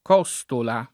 costola [ k 0S tola ] s. f.